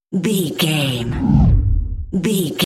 Deep whoosh pass by
Sound Effects
dark
intense
whoosh